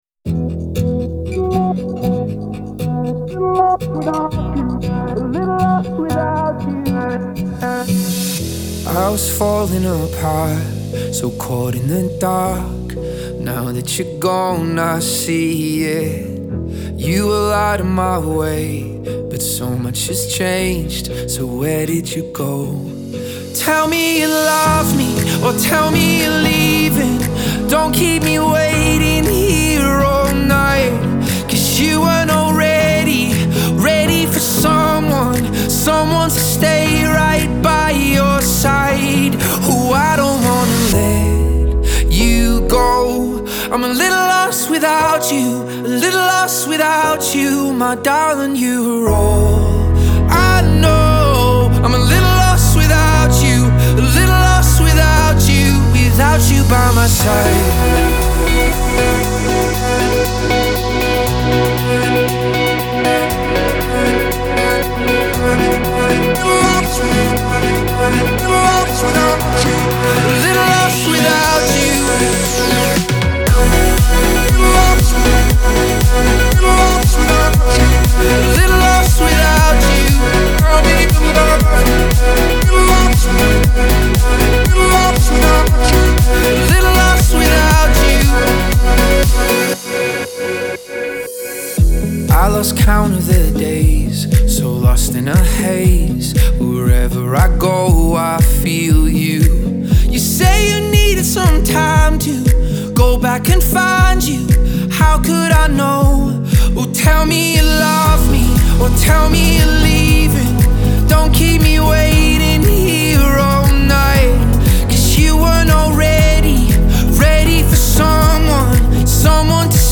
Genre : Dance